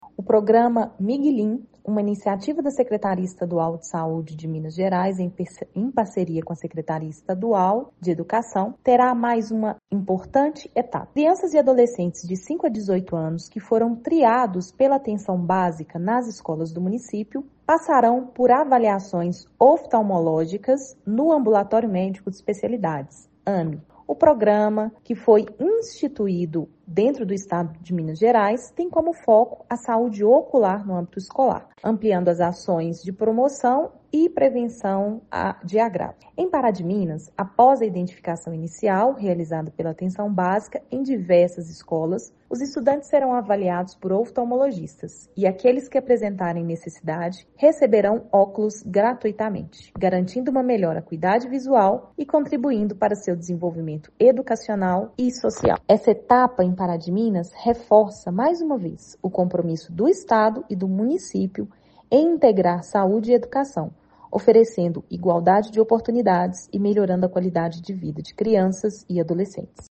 A partir de segunda-feira, 09 de dezembro, o Programa Miguilim entrará em uma nova fase em Pará de Minas. Crianças e adolescentes de 5 a 18 anos passarão por avaliações oftalmológicas no Ambulatório Médico de Especialidades (AME) e quem precisar receberá os óculos gratuitamente, como afirma a secretária municipal de Saúde, Ana Clara Teles Meytre: